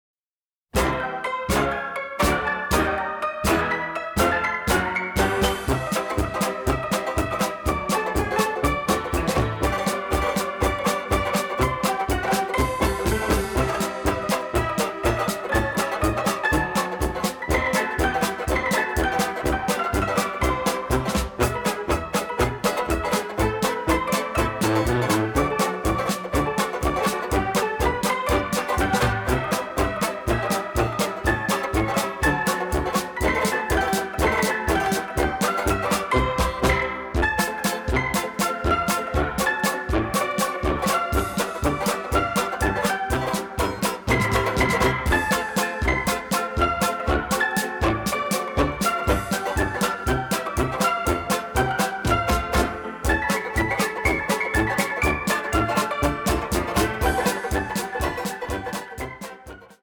and a funny silent film pastiche.